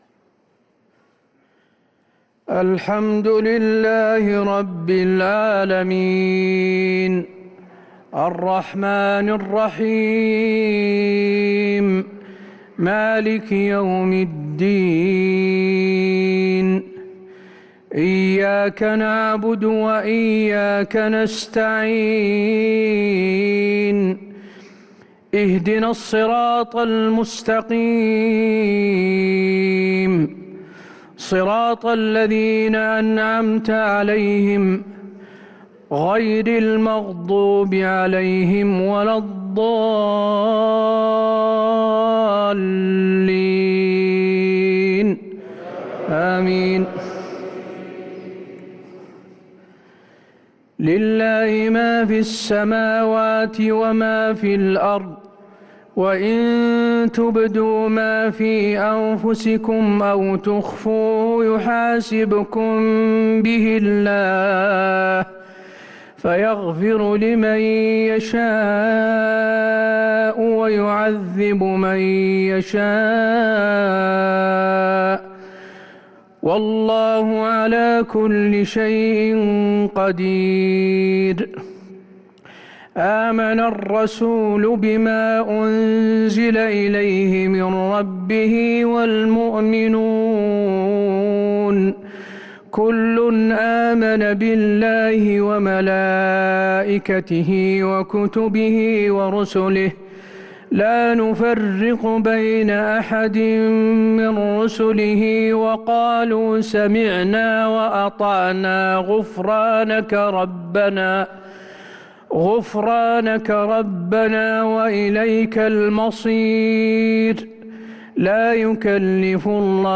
صلاة العشاء للقارئ حسين آل الشيخ 21 جمادي الأول 1444 هـ
تِلَاوَات الْحَرَمَيْن .